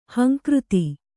♪ hankřti